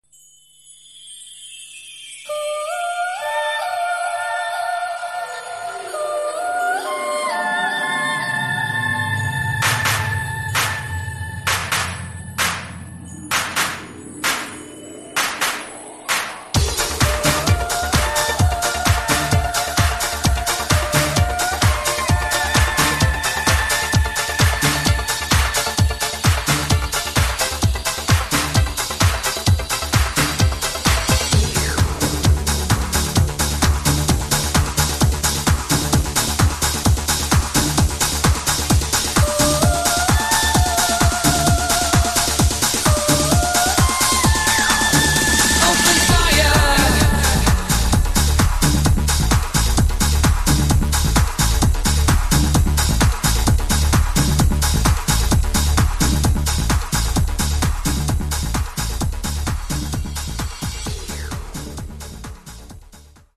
mixed & remixed by various DJs